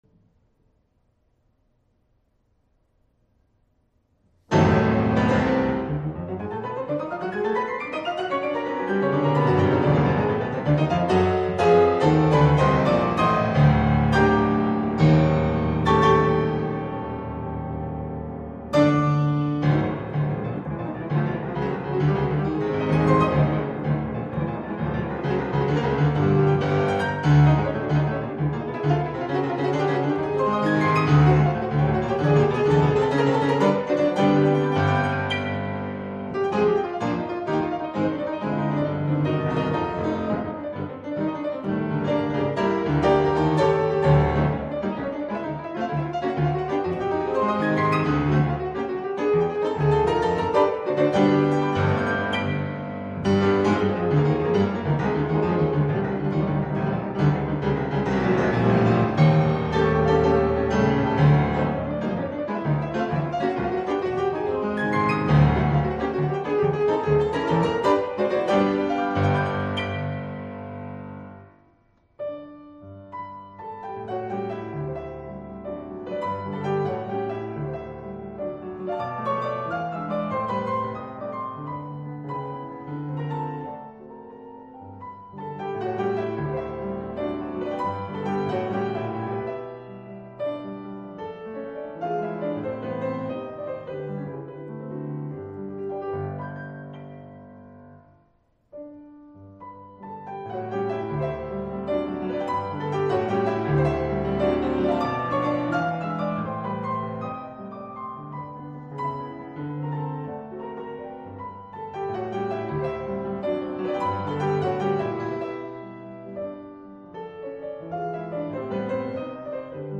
martellato.mp3